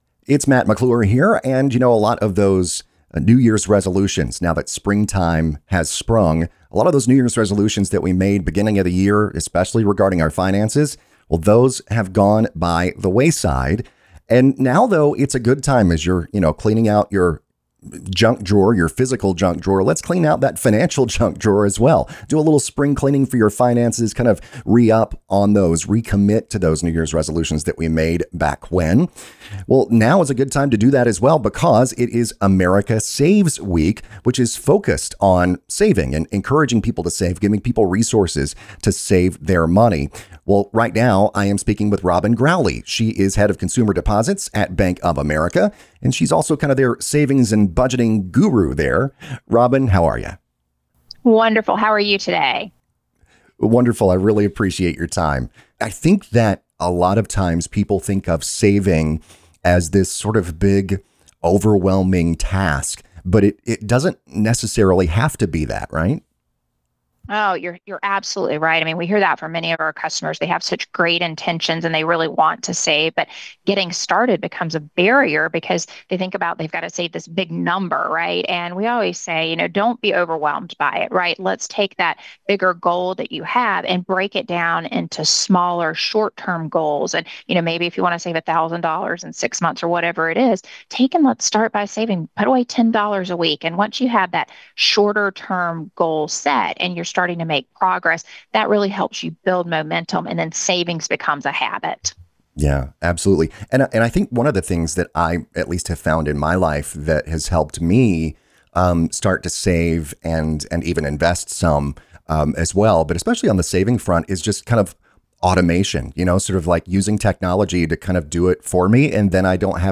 Bonus Interview: Spring Clean Your Finances for America Saves Week